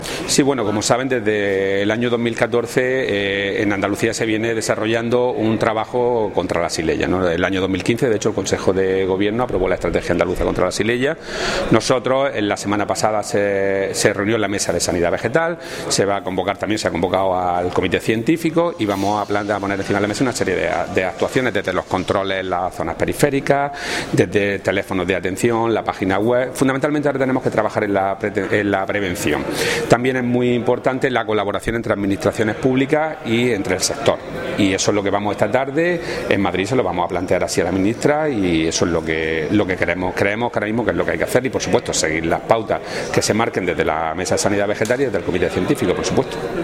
Declaraciones de Rodrigo Sánchez Haro sobre Xylella fastidiosa